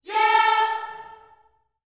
YEAH A 4A.wav